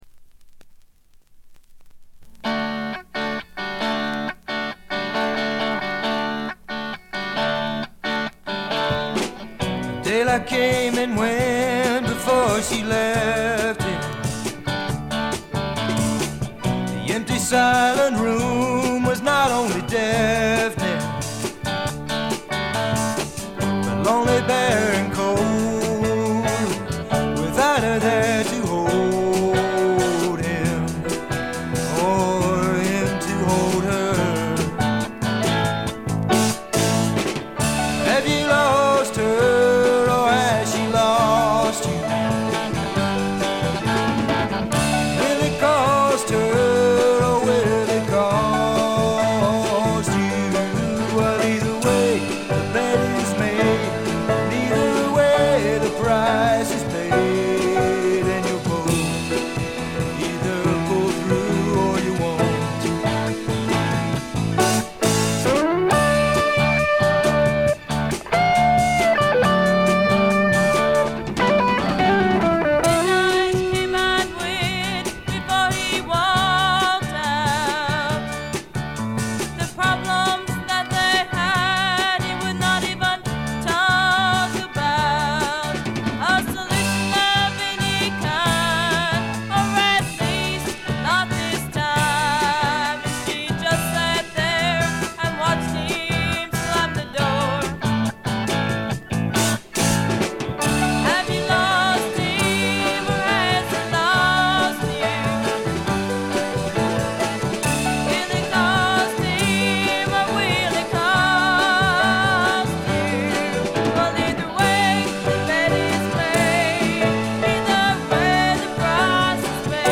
ノース・カロライナ産の自主制作サイケ／フォークロックの傑作です。
79年という時代性はほぼゼロです。録音もしょぼくてこの時代によくこんなチープに作れたなぁと感心してしまいますね。
試聴曲は現品からの取り込み音源です。